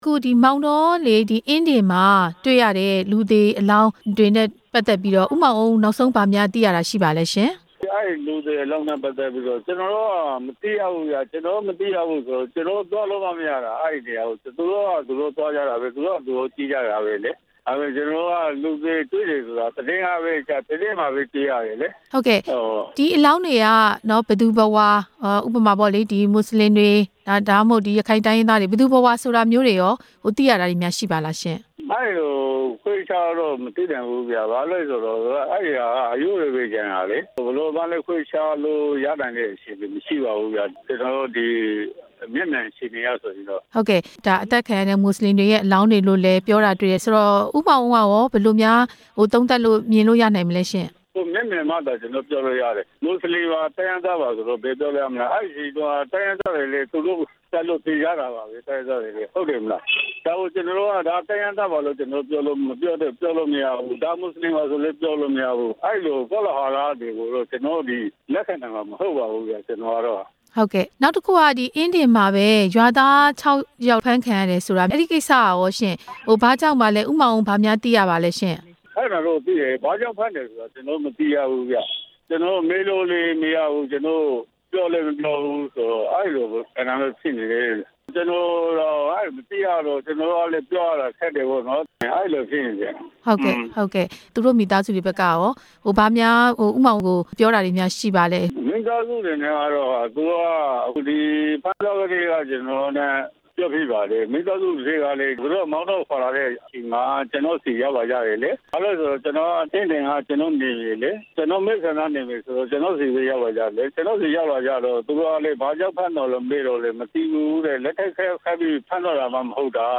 လူသေရုပ်အလောင်းတွေ တွေ့ရှိမှု မောင်တောအမတ်နဲ့ မေးမြန်းချက်